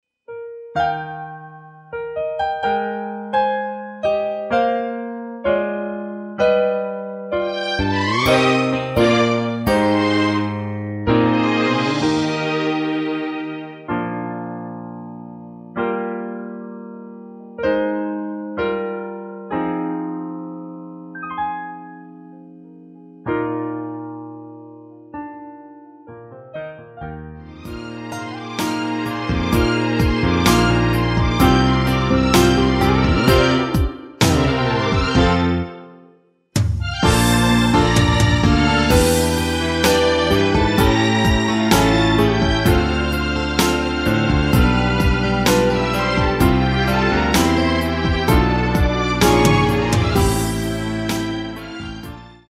원키에서(+5)올린 MR입니다.
원곡의 보컬 목소리를 MR에 약하게 넣어서 제작한 MR이며